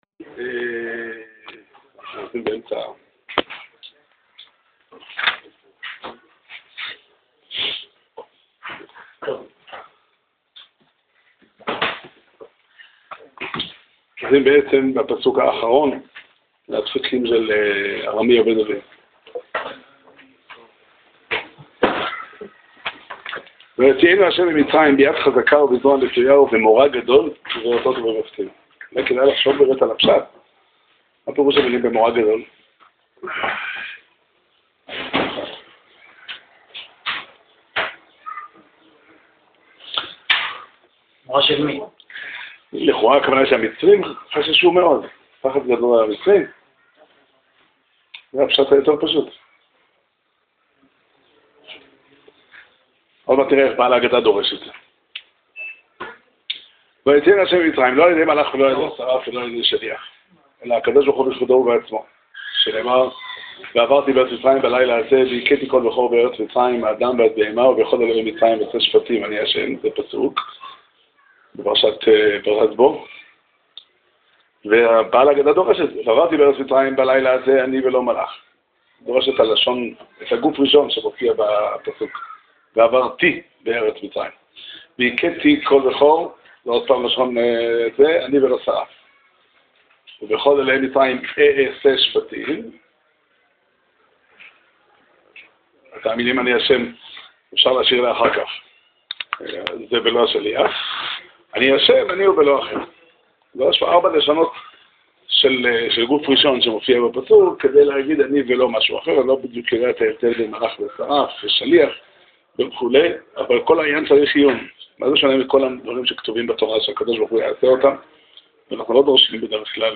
שיעור שנמסר בבית המדרש 'פתחי עולם' בתאריך כ"ח אדר תשע"ח